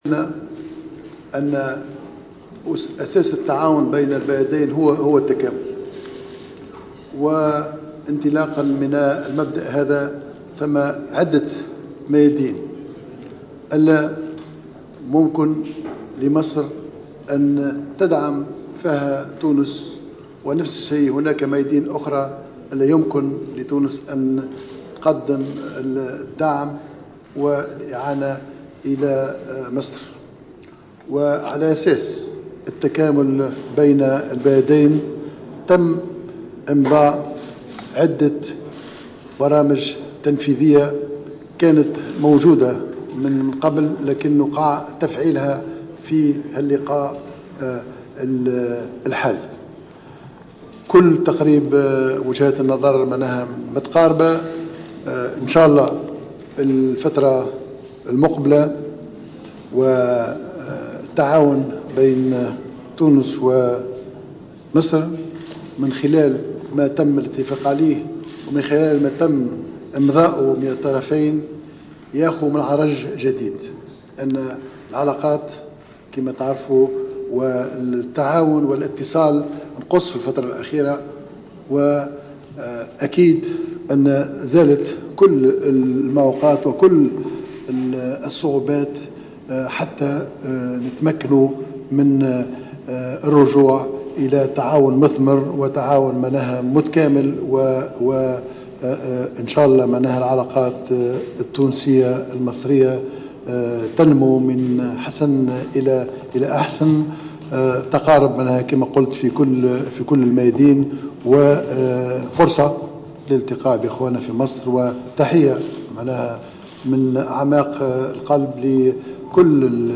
قال رئيس الحكومة الحبيب الصيد خلال مؤتمر صحفي جمعه بنظيره المصري ابراهيم محلب على إثر اجتماع اللجنة العليا المشتركة التونسية المصرية اليوم الثلاثاء، إن هنالك توافق في وجهات النظر بين الطرفين المصري والتونسي فيما يتعلق بالقضايا الراهنة وخاصة الوضع في سوريا واليمن وليبيا.